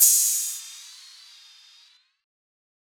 TR 808 Free sound effects and audio clips
• Open High-Hat G# Key 27.wav
Royality free open hi hat sample tuned to the G# note. Loudest frequency: 8158Hz
open-high-hat-g-sharp-key-27-xB3.wav